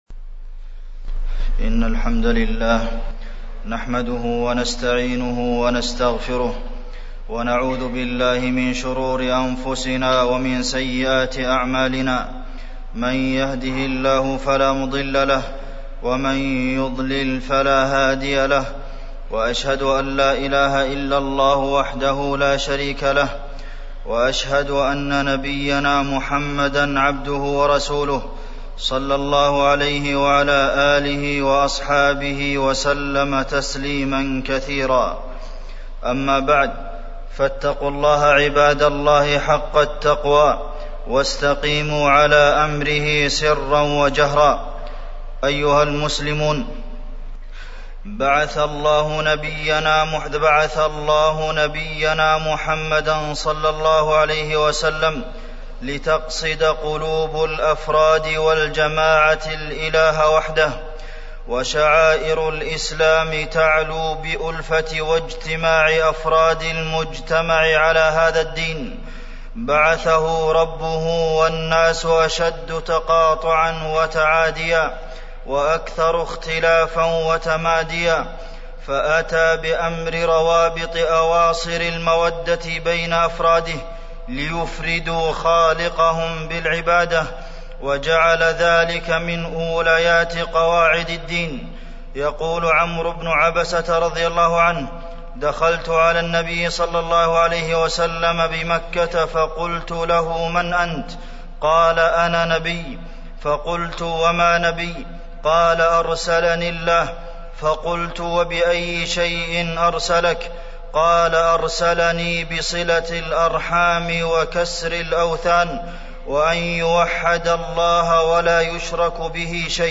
تاريخ النشر ٧ رجب ١٤٢٦ هـ المكان: المسجد النبوي الشيخ: فضيلة الشيخ د. عبدالمحسن بن محمد القاسم فضيلة الشيخ د. عبدالمحسن بن محمد القاسم صلة الرحم